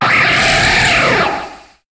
Cri de Tokorico dans Pokémon HOME.